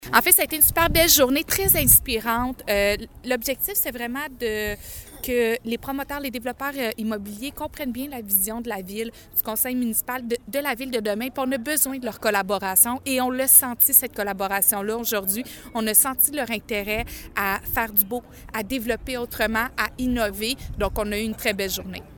La mairesse de Granby, Julie Bourdon se réjouit de voir la collaboration et l’intérêt des promoteurs immobiliers :